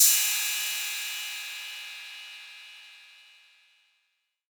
808CY_1_Orig_ST.wav